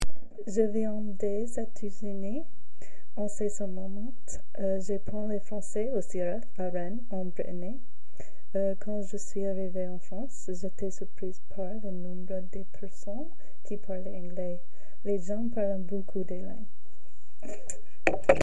Cabine de témoignages
Témoignage du 24 novembre 2025 à 16h40